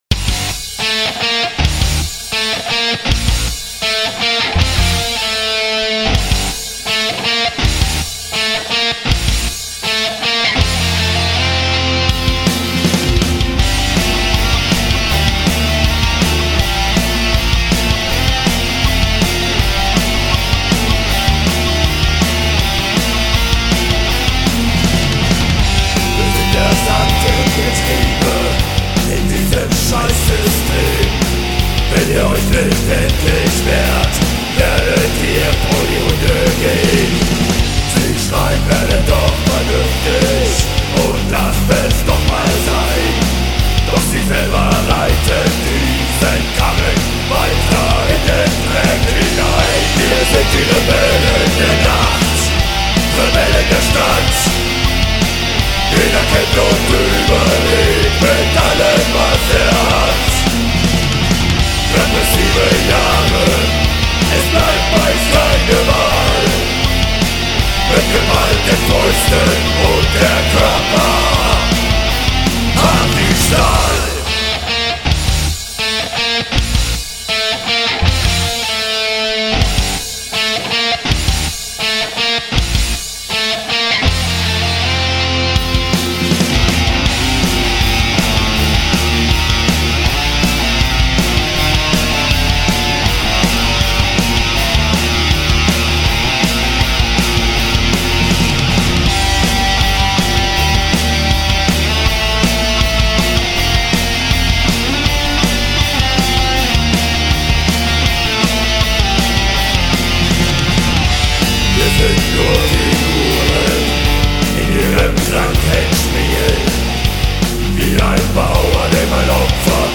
Deutschrock